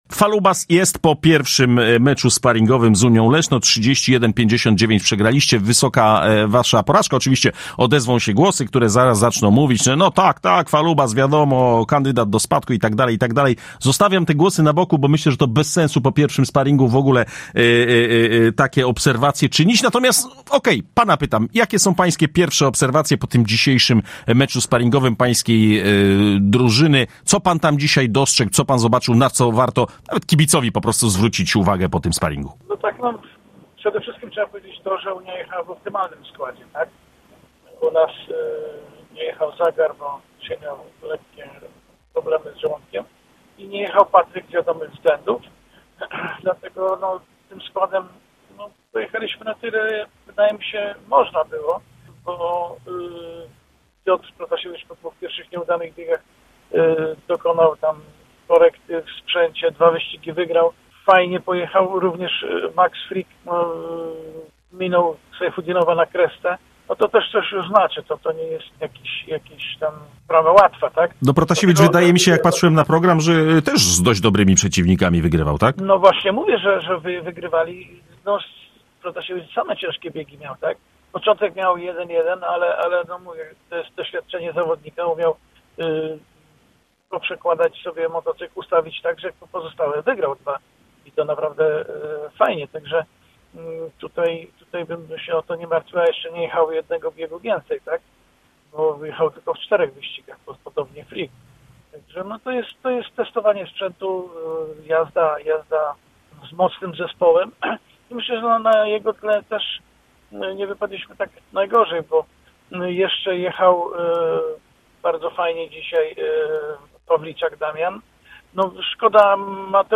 Żużlowcy Falubazu przegrali dziś w Lesznie sparring z miejscową Unią 31-59. Po meczu rozmawialiśmy